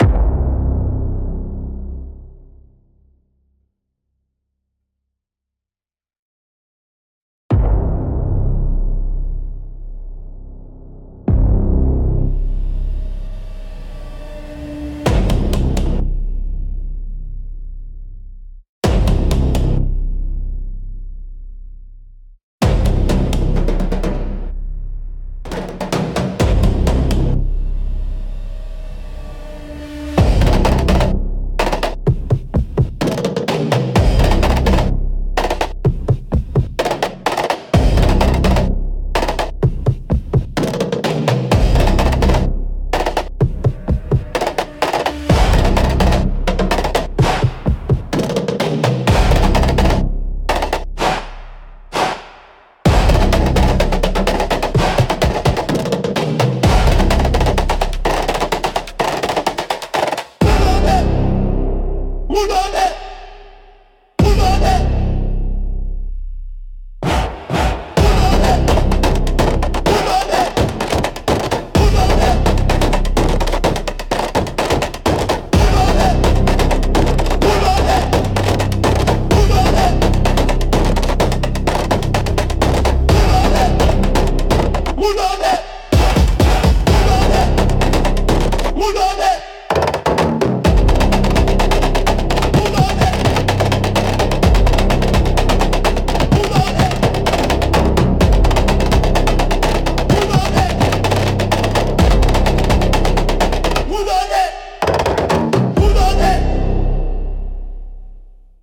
Instrumental - Synaptic Chant - 1.48